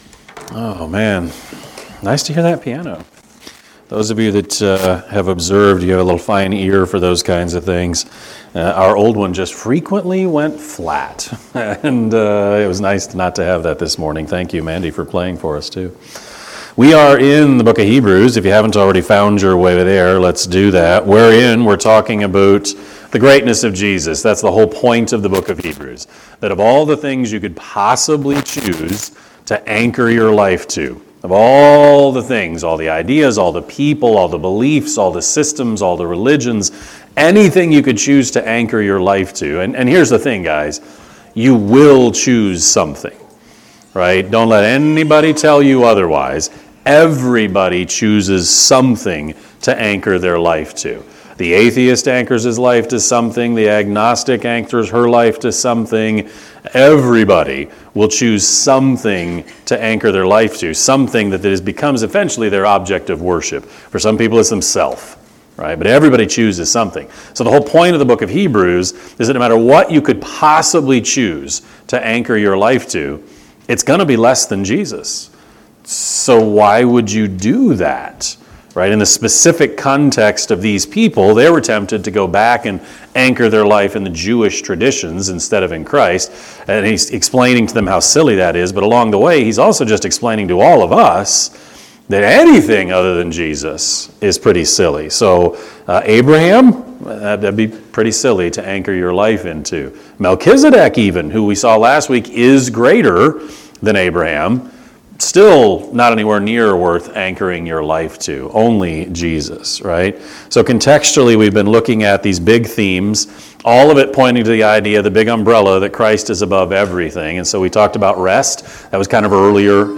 Sermon-8-24-25-Edit.mp3